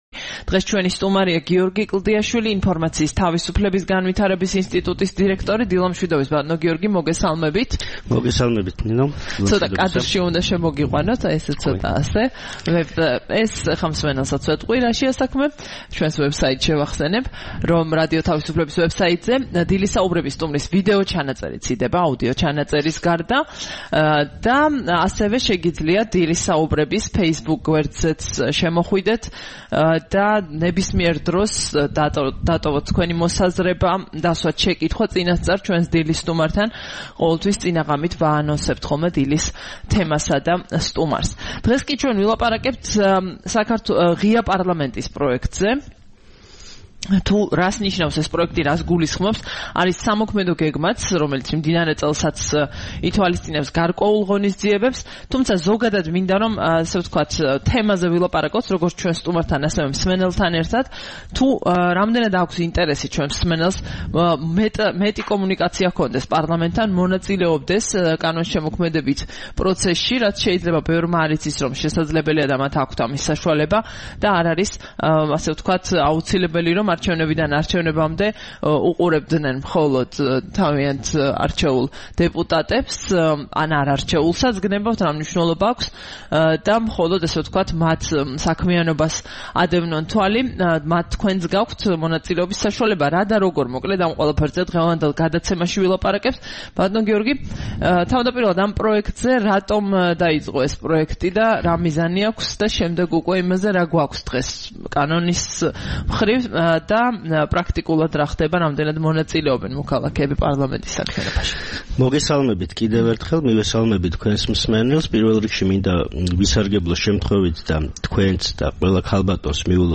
რადიო თავისუფლების „დილის საუბრების“ სტუმარი